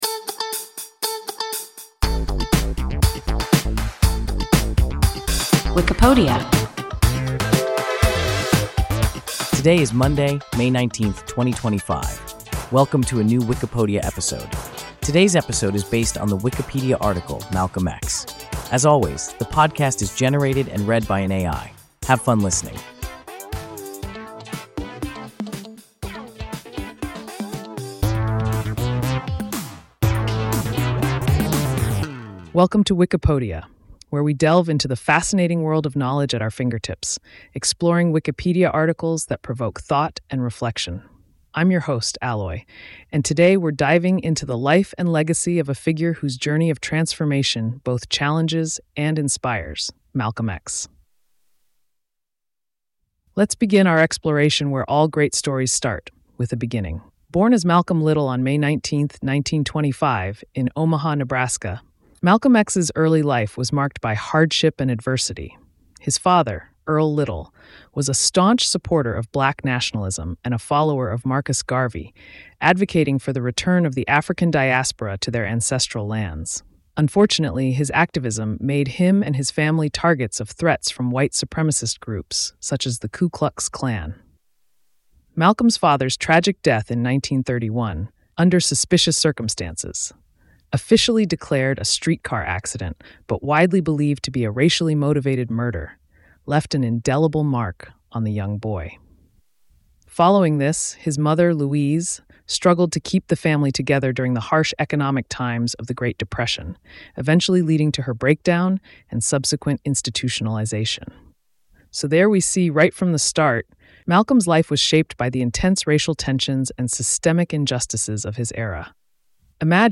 Malcolm X – WIKIPODIA – ein KI Podcast